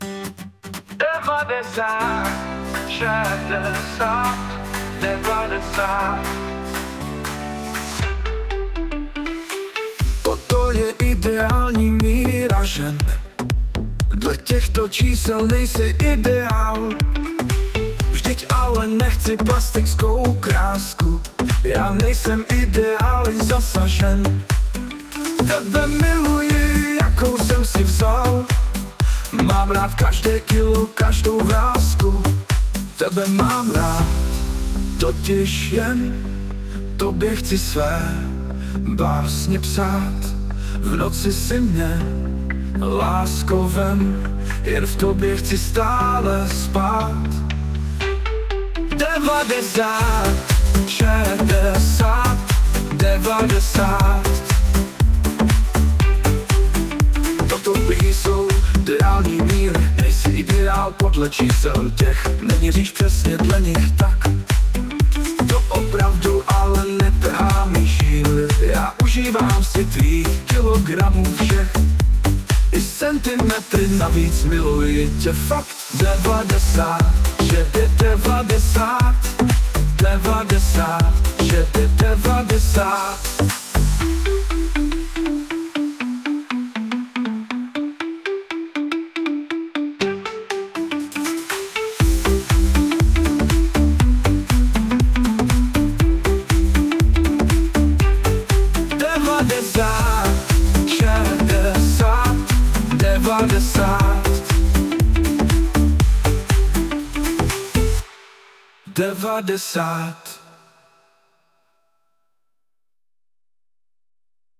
hudba, zpěv, obrázek: AI